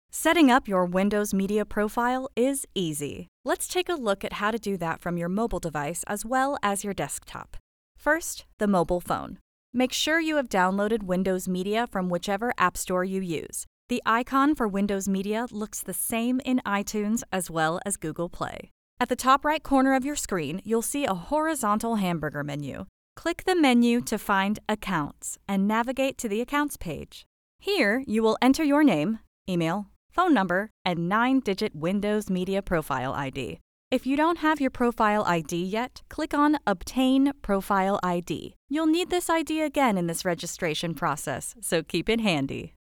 E-learning and Corporate Narration Sample